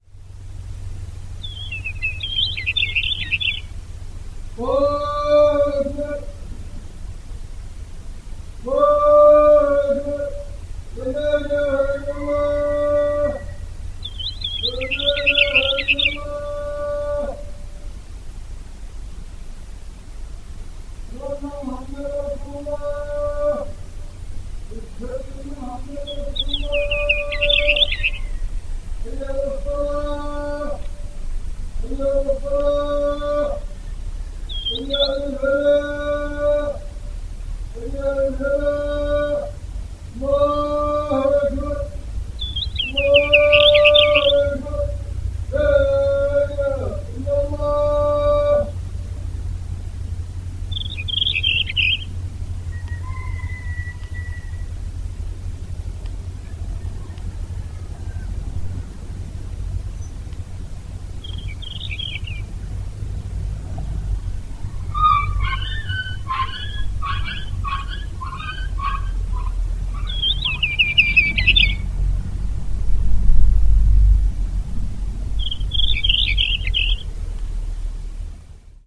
描述：在钢筋/轮询中产生的振动和隆隆声的硬录音。在ZOOM H6上进行了修改。
Tag: 钢条 颤抖 频率 隆隆声 硬的声音 效果 振动 低音 OWI